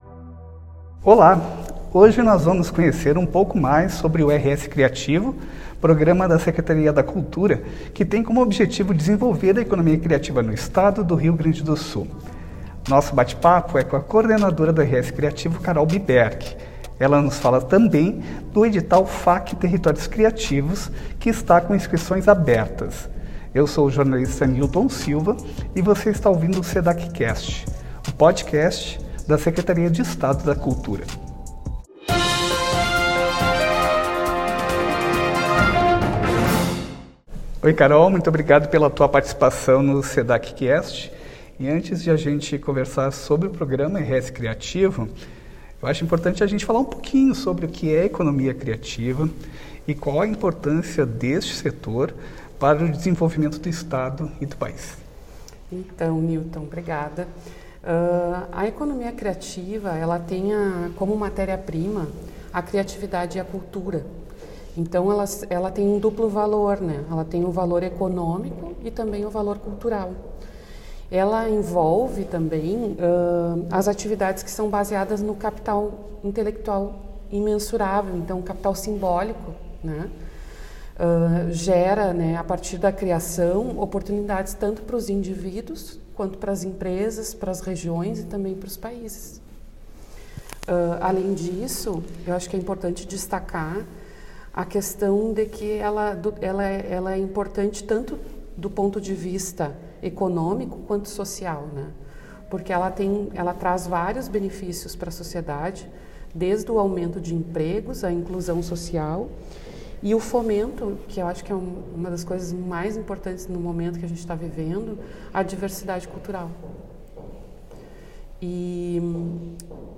Sedac Cast #17 | Entrevista